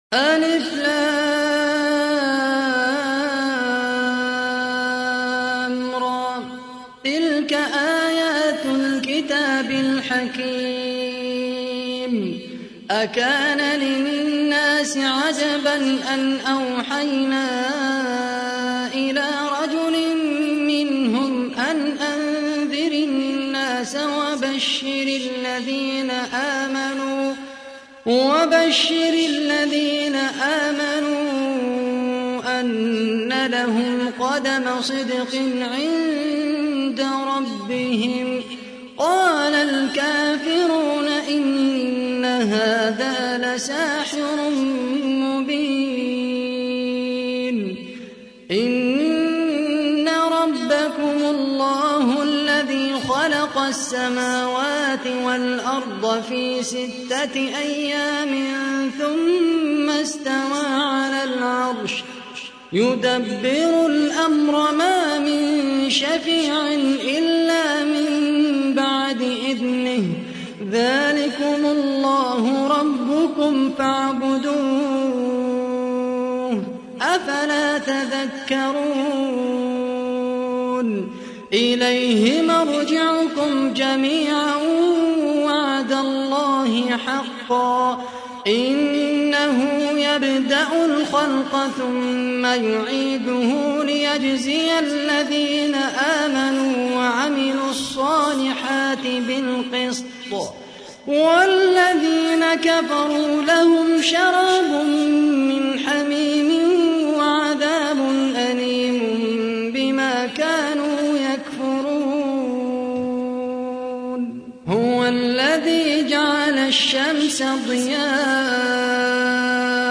تحميل : 10. سورة يونس / القارئ خالد القحطاني / القرآن الكريم / موقع يا حسين